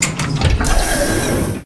dooropen5.ogg